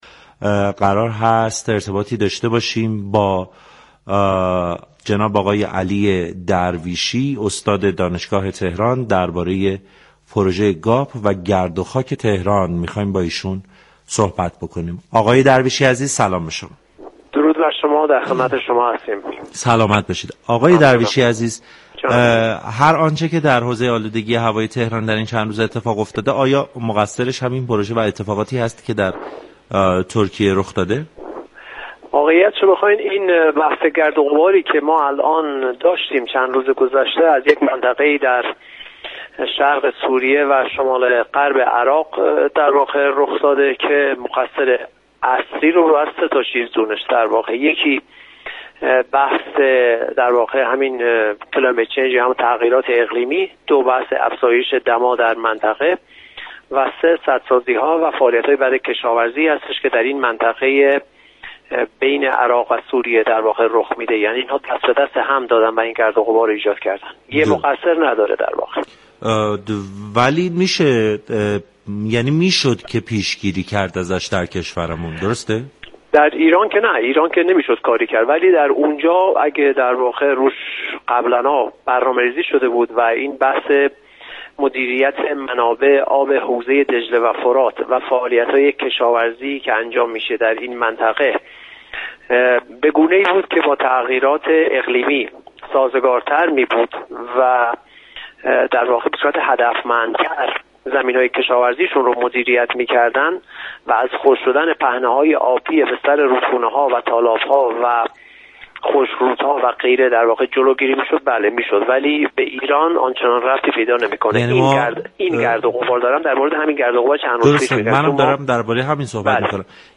در گفت و گو با برنامه سعادت آباد رادیو تهران